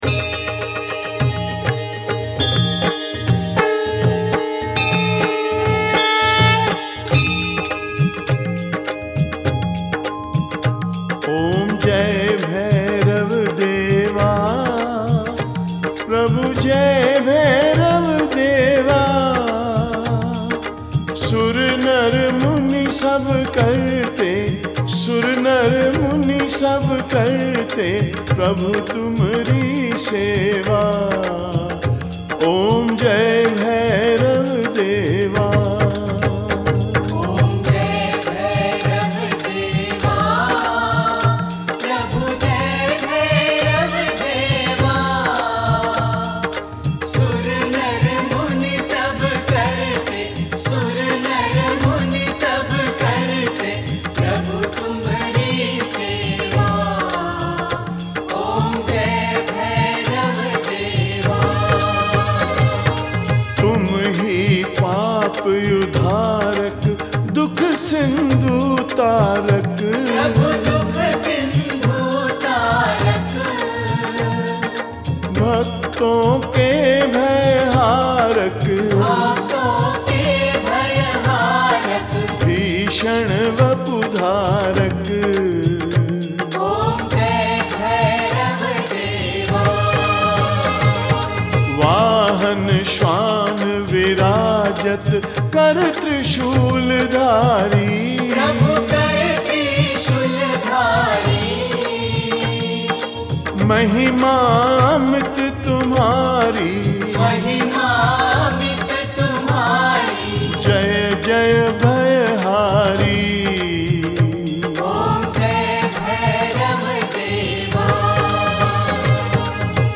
Aartiyan